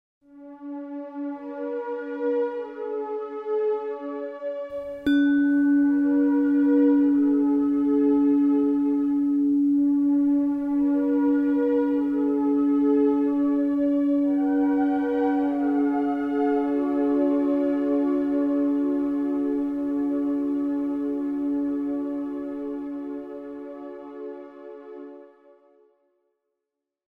Sie hören jeweils eine begleitende auf die jeweilige Frequenz abgestimmte Tonfolge und die Klangröhre, die exakt in der entsprechenden Frequenz schwingt.
285 Hz (Quantum, Kognition)Komposition "Genesung"
285 Hz I.mp3